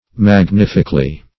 -- Mag*nif"ic*al*ly , adv.
magnifically.mp3